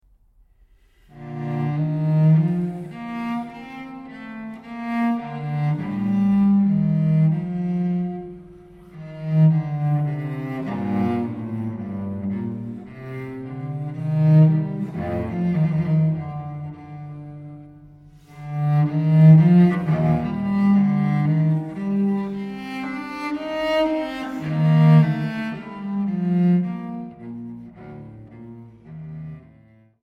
Transkiptionen für Violoncello